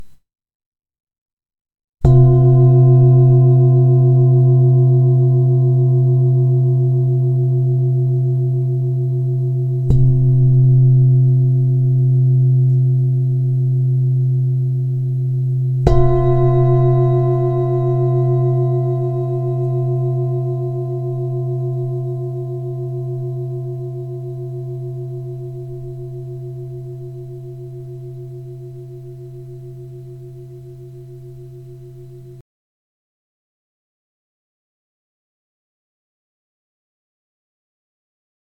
Ramgondi tibetská mísa C 26,5cm
Mají hluboký zvuk a nejvíce se podobají zvonům.
Nahrávka mísy úderovou paličkou:
Mísa je však velice oblíbená i díky svému hlubšímu zvuku, který vybízí k relaxaci, odpočinku a ukotvení v tady a teď.
Její tajemný zvuk vás vtáhne do meditačního světa plného klidu a mystiky.
Jde o ručně tepanou tibetskou zpívající mísu dovezenou z Indie.